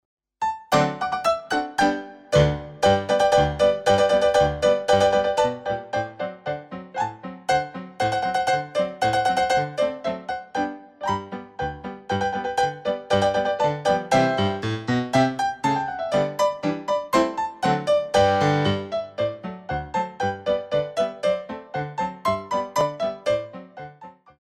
Show Tunes for Ballet Class
Jetes
4/4 (16x8)